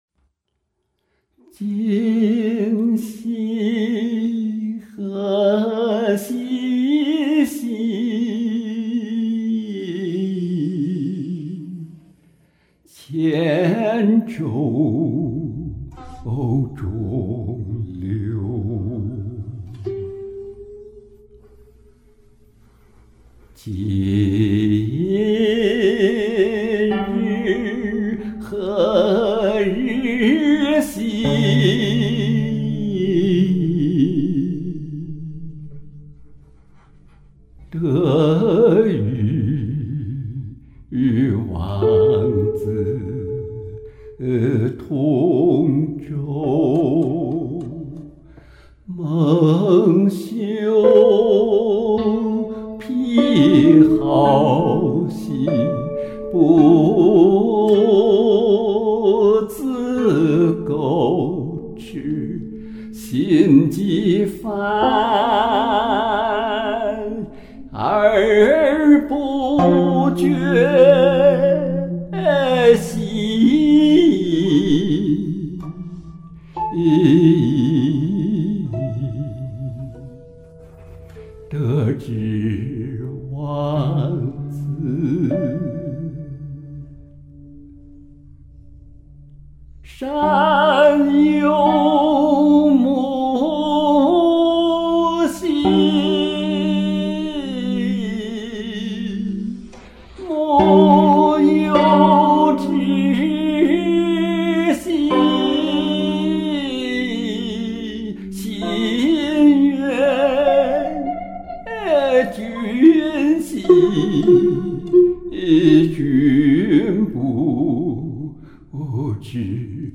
很动情的歌声，古韵古风，唱得真情，很有感染力！
几乎是清唱呀！
古韵悠扬，情意绵长。
好空旷深远的歌声，